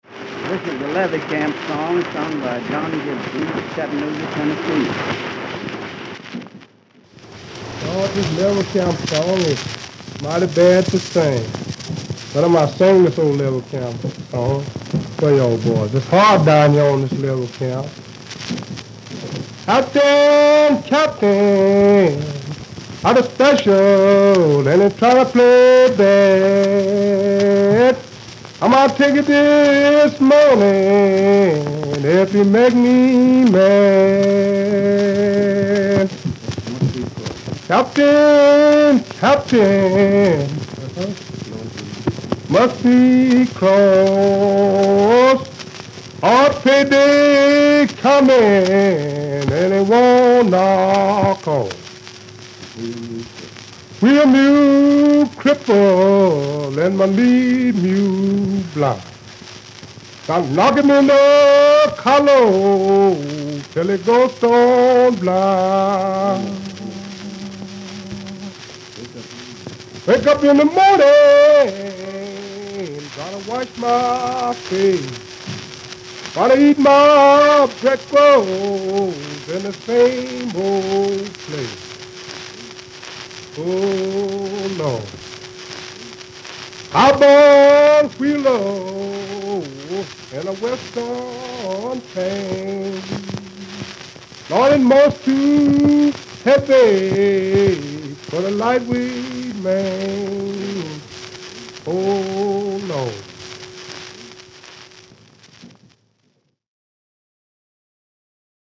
Field hollers